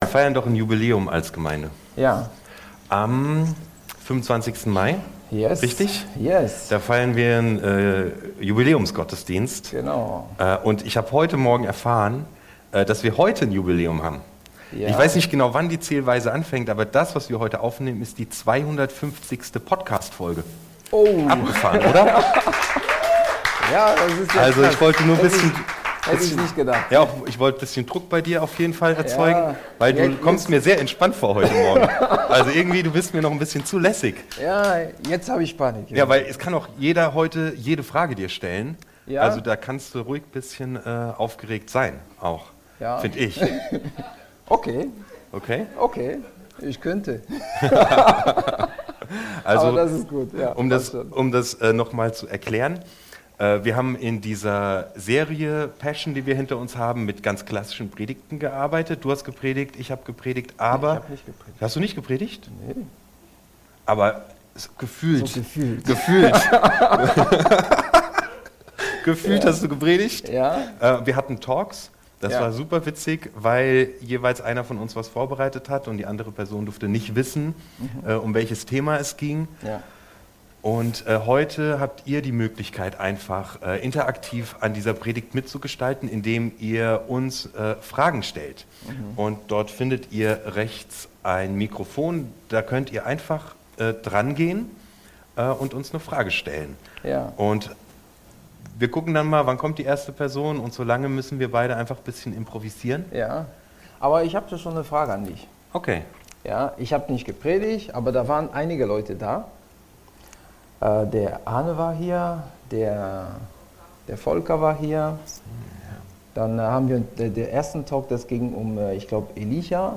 Was willst du? 2: Wer bist du? – Predigt-Podcast von "unterwegs" FeG Mönchengladbach – Lyssna här – Podtail